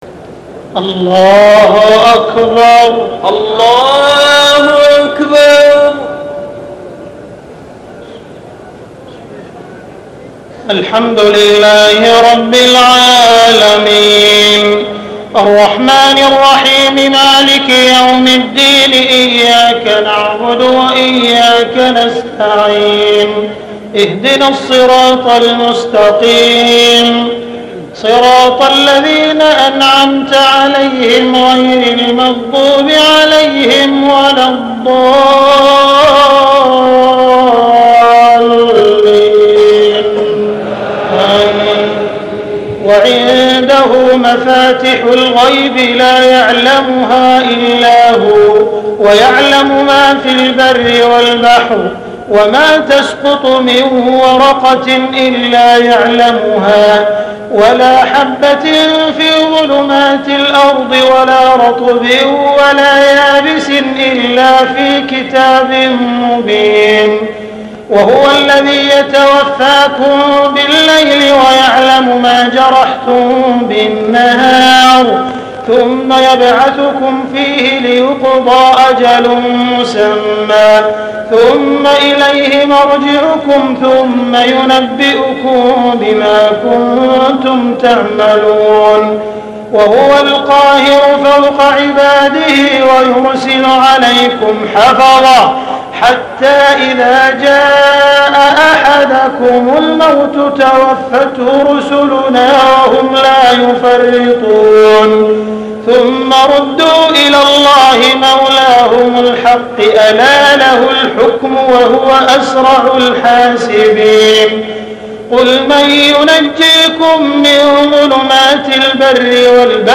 تهجد ليلة 27 رمضان 1425هـ من سورة الأنعام (59-111) Tahajjud 27 st night Ramadan 1425H from Surah Al-An’aam > تراويح الحرم المكي عام 1425 🕋 > التراويح - تلاوات الحرمين